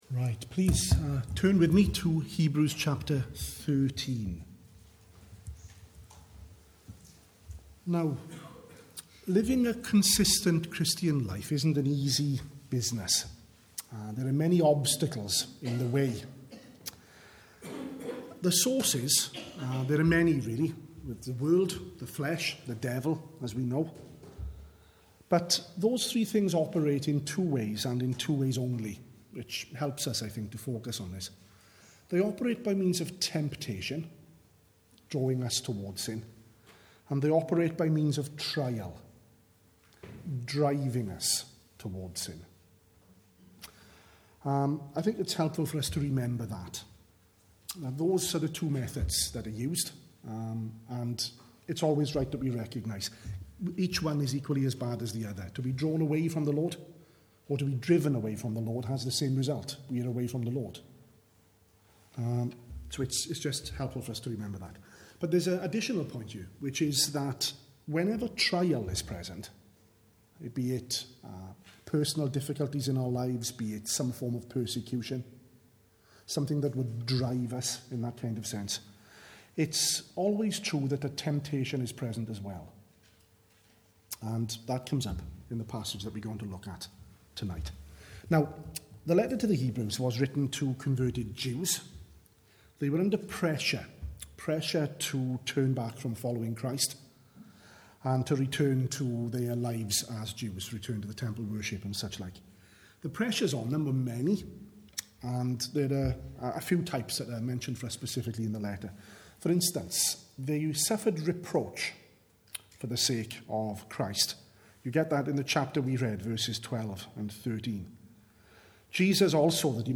at the evening service